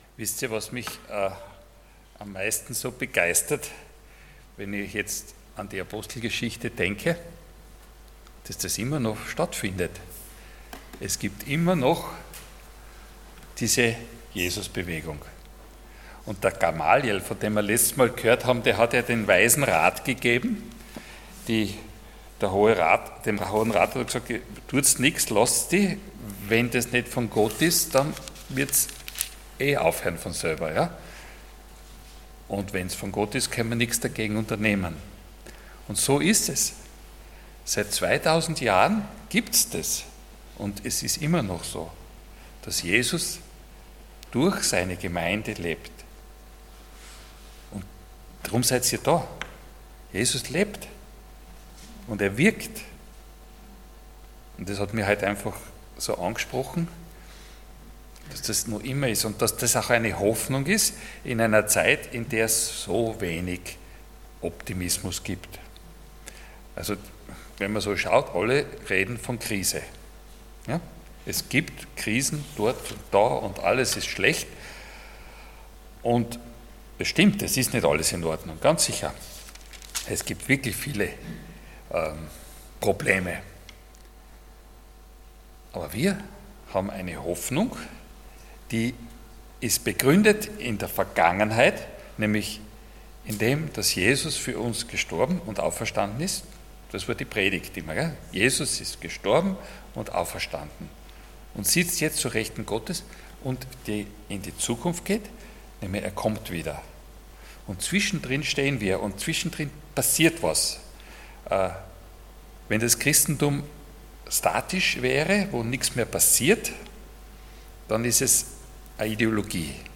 Passage: Acts 6:1-7 Dienstart: Sonntag Morgen